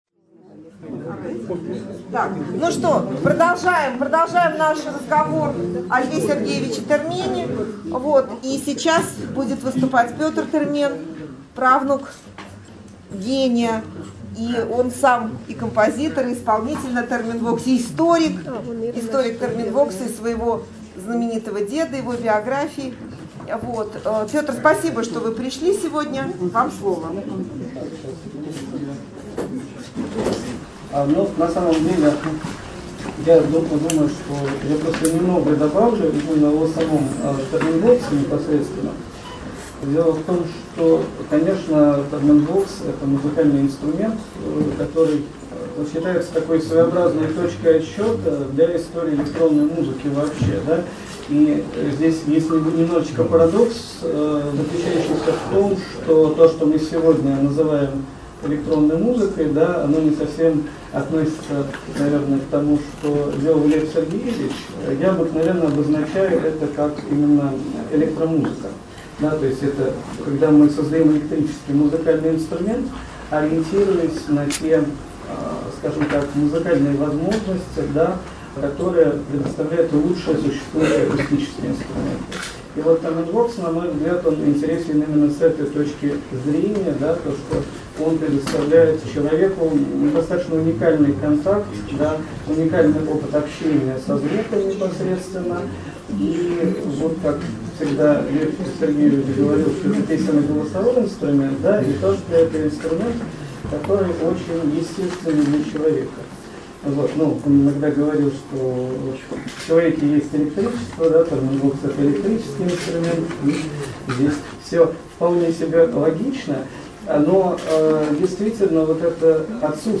5 мая 2018 в Музее-библиотеке прошёл вечер посвящённый Льву Сергеевичу Термену (1896-1993), знаменитому музыканту и изобретателю.